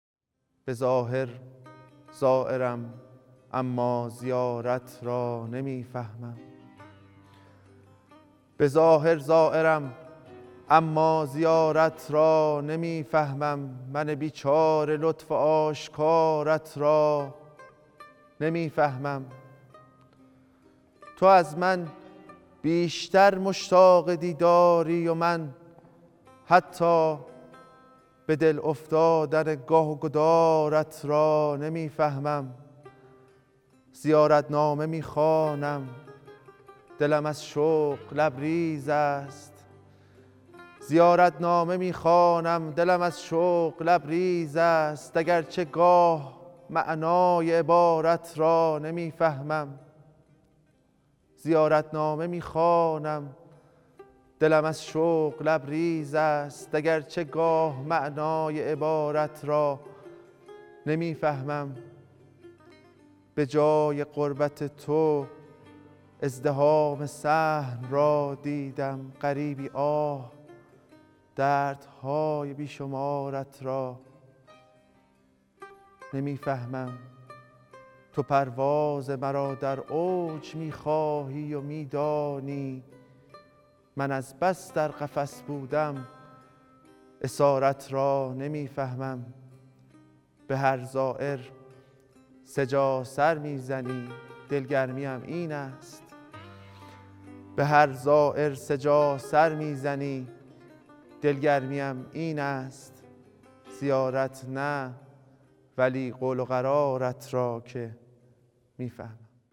شعرخوانی | به ظاهر زائرم ....
مسجد مقدس جمکران_شهر مقدس قم | روایت هیأت فصل دوم